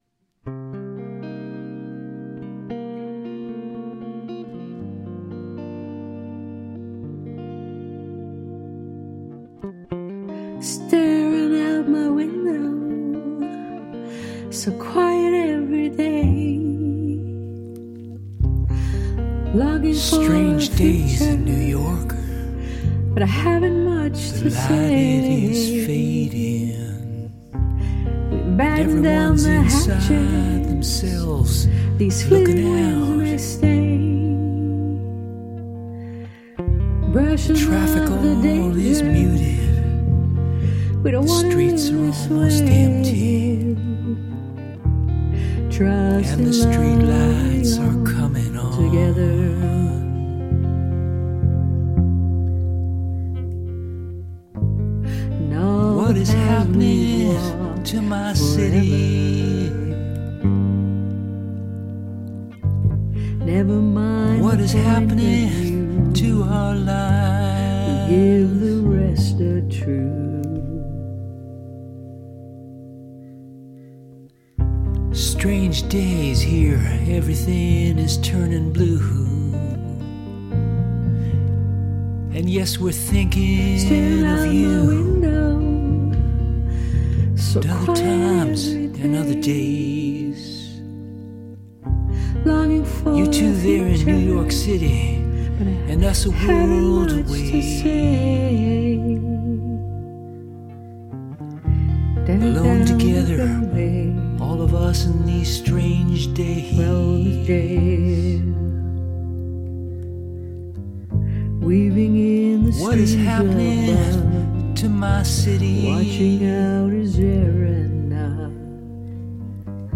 groove oriented
Vocals, Acoustic and Rhythm Guitar, Navajo Flute
Bass, Lead Guitar, Hand Percussion and Drum Programming
Drums and Percussion
Keys (Organ, Synth, Piano)
Blues Harp, Ocarina and Penny Whistle